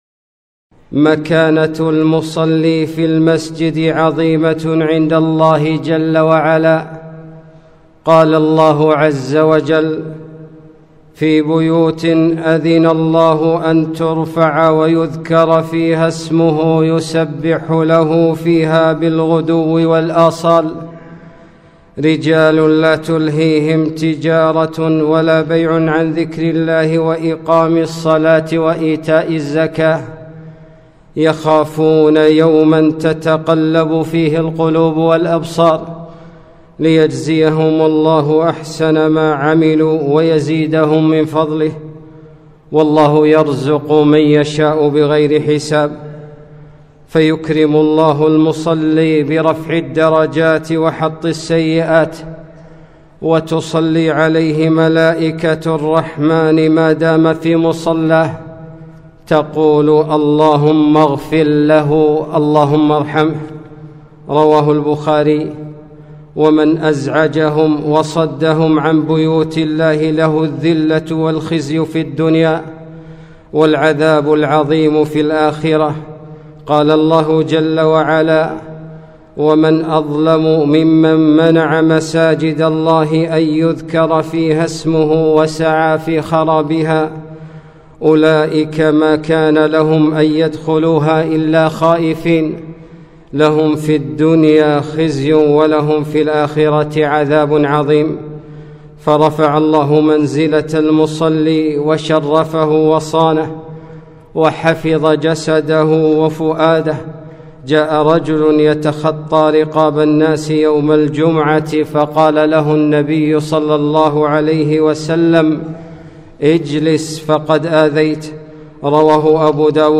خطبة - لا تؤذوه